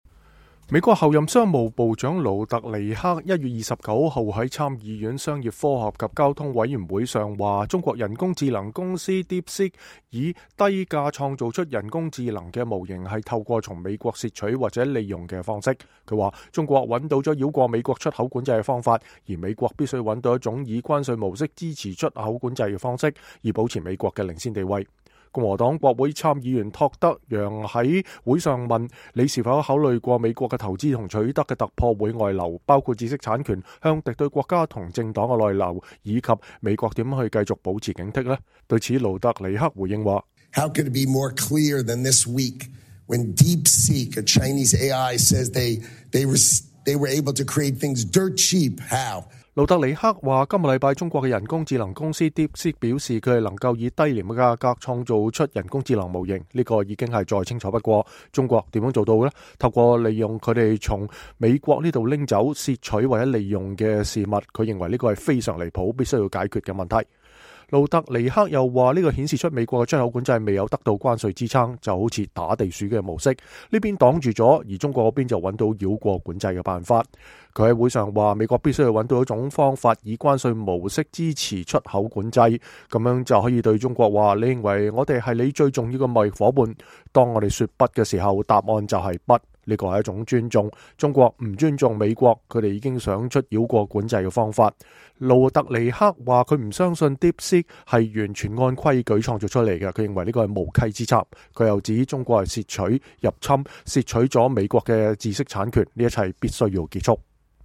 美國候任商務部長霍華德·盧特尼克(Howard Lutnick)1月29日在參議院商業、科學和交通委員會上表示，中國人工智能公司DeepSeek以低價創造出人工智能模型，是透過從美國竊取或利用的方式。他說，中國找到了繞過美國出口管制的方法，而美國必須找到一種以關稅模式支持出口管制的方式，以保持美國的領先地位。